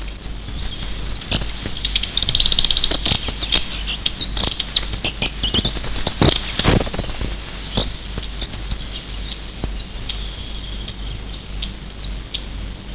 Questi piccoli uccelli si muovono incessantemente tra i rami delle querce e degli ornelli alla ricerca di cibo, compiendo brevi voli tra un albero ed un'altro ed emettendo spesso dei caratteristici tenui